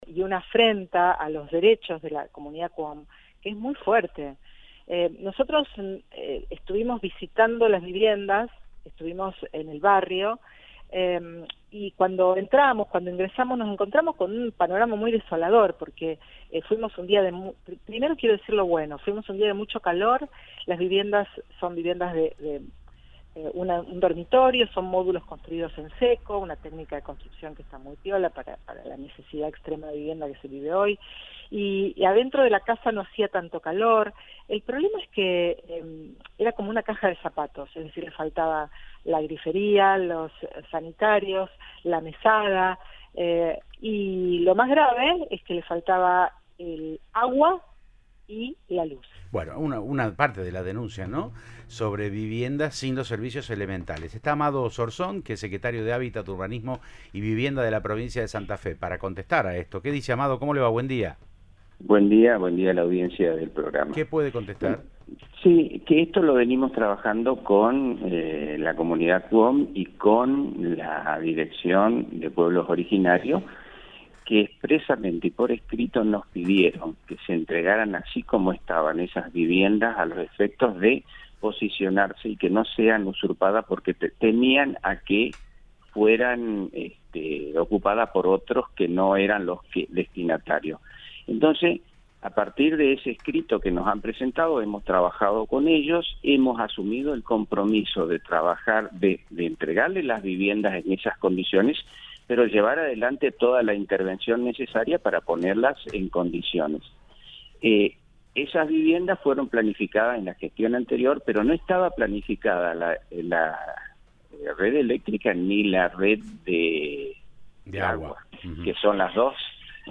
Amado Zorzon, Secretario de Hábitat, Urbanismo y Vivienda, dialogó con Siempre Juntos de Cadena 3 Rosario y dijo que “la propia Comunidad Qom pidió que se las entreguemos así para evitar ocupaciones”.